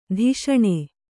♪ dhish'aṇe